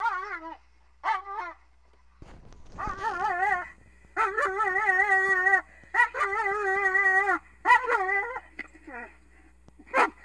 Dog whining.wav